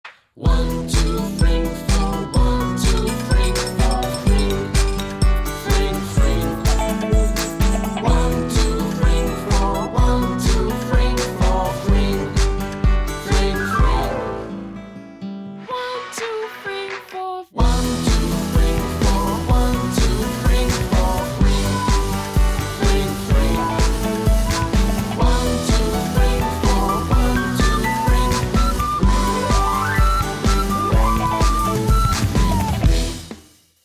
獨家鈴聲